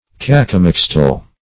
Search Result for " cacomixtle" : The Collaborative International Dictionary of English v.0.48: Cacomixle \Ca`co*mix"le\, Cacomixtle \Ca`co*mix"tle\, Cacomixl \Ca"co*mix`l\, n. [Mexican name.]
cacomixtle.mp3